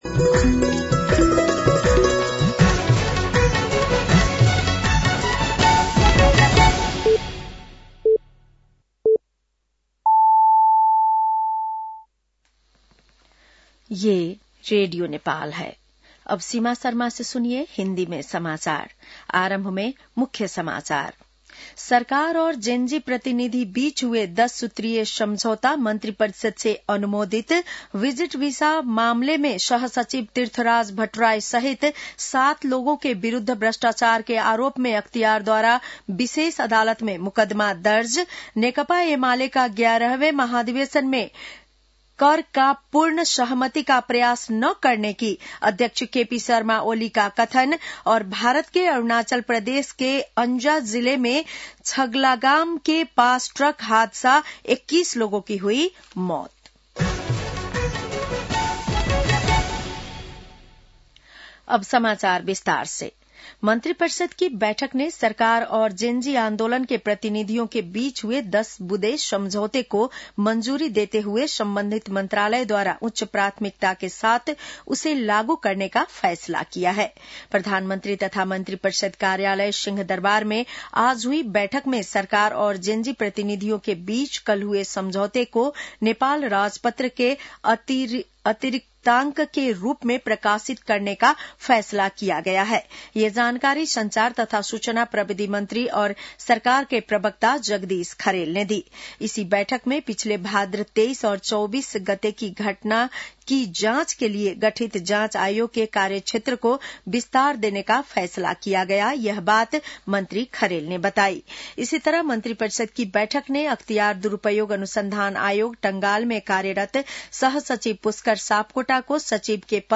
बेलुकी १० बजेको हिन्दी समाचार : २५ मंसिर , २०८२
10-PM-Hindi-NEWS-8-25.mp3